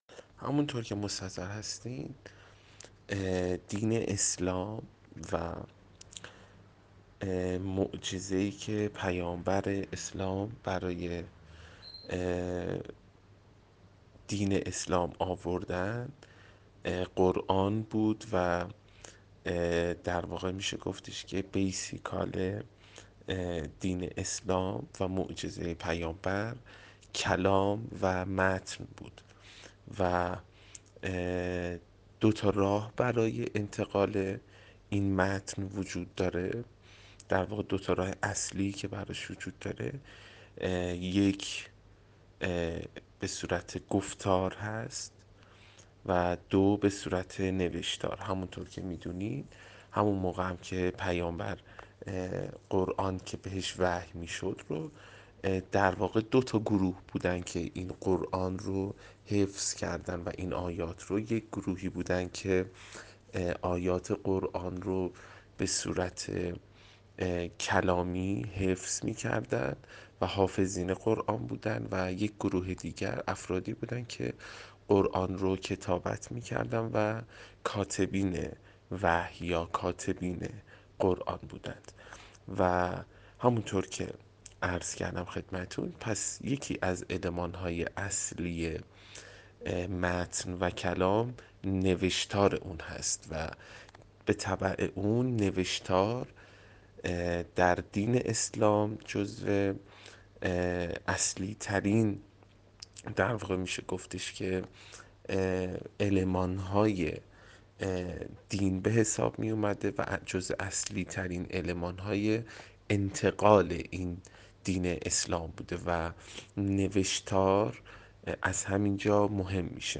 یک هنرمند نقاشیخط گفت: خوشنویسی پیوند خورده با کتابت قرآن است و بدون هیچ شکی رتبه نخست را در هنر اسلامی دارد.